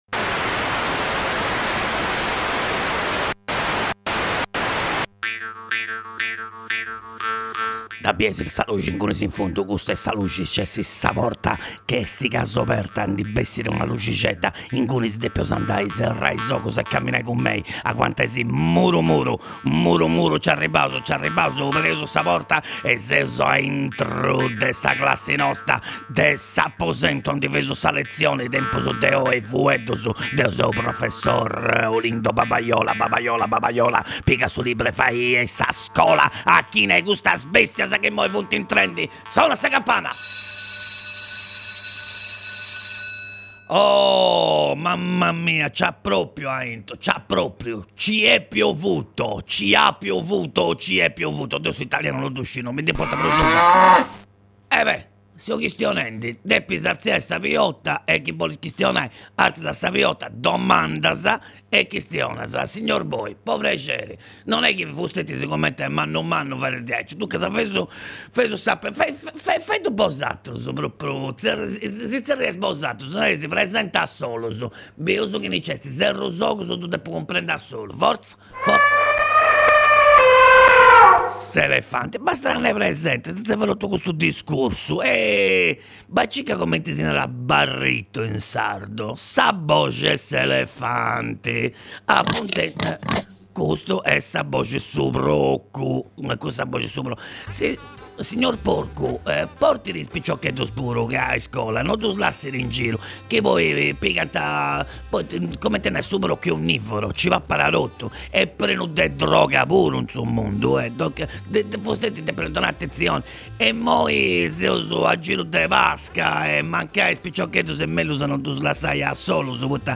Professor Olindo Babaiola dogna di’ ìntrat a fura in is undas de Radio X po fai scola de sardu.